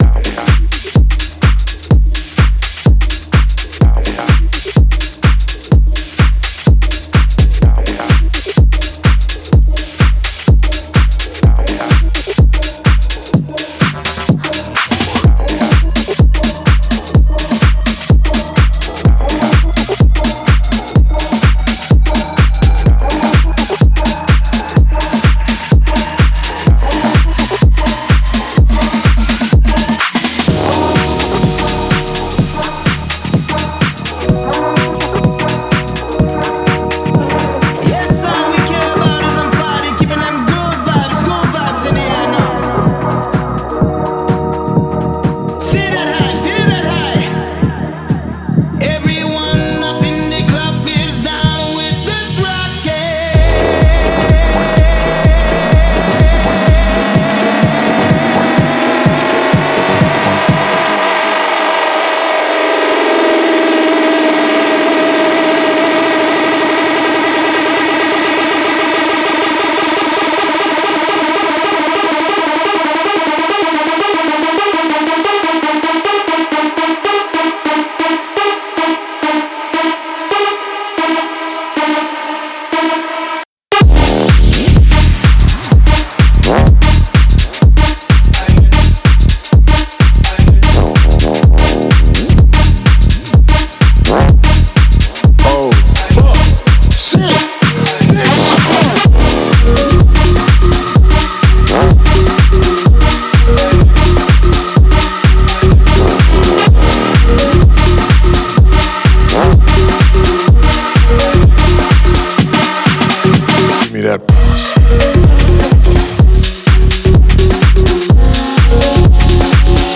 试听文件为低音质，下载后为无水印高音质文件 M币 15 超级会员 M币 8 购买下载 您当前未登录！